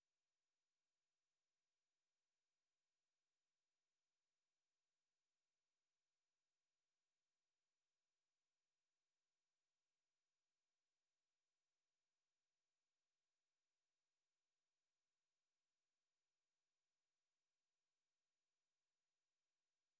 backnoise (2).wav